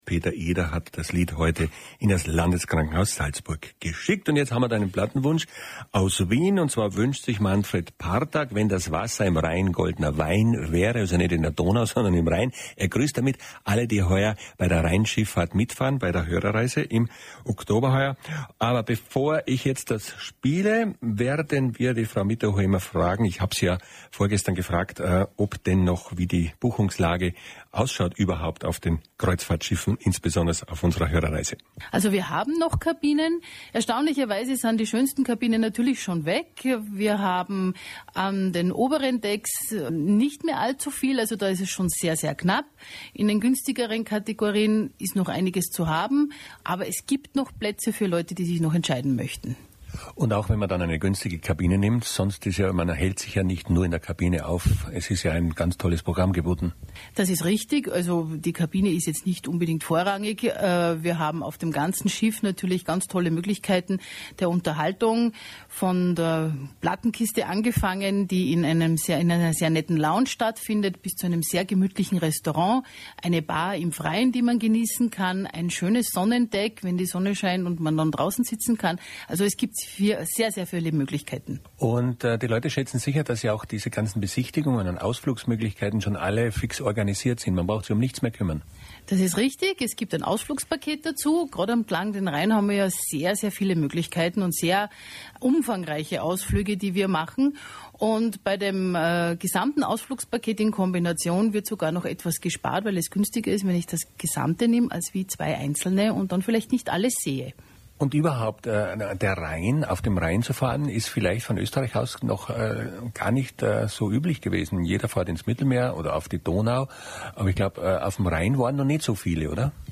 In der 426. Plattenkiste vom 17.3.2013 war wieder ein Interview mit eingepackt.